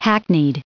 added pronounciation and merriam webster audio
379_hackneyed.ogg